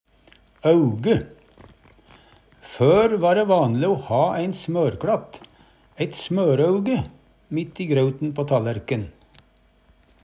auge - Numedalsmål (en-US)